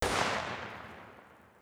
SMG1_ShootTail 01.wav